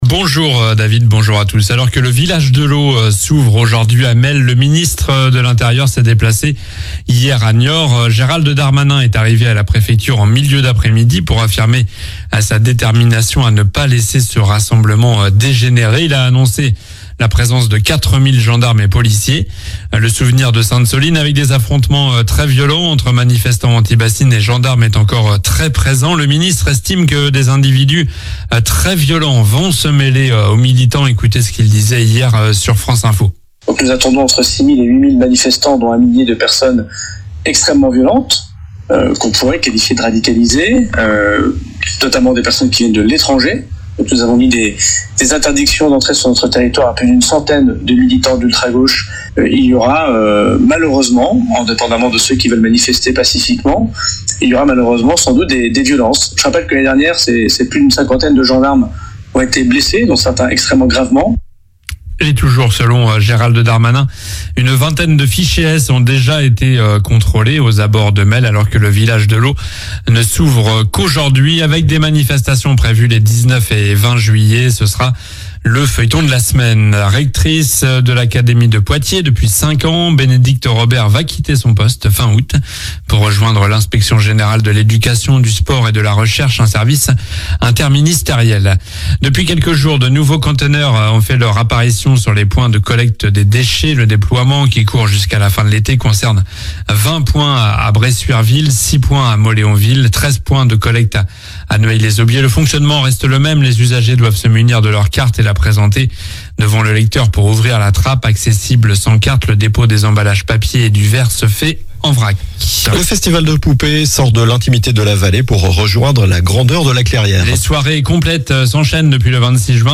Journal du mardi 16 juillet (matin)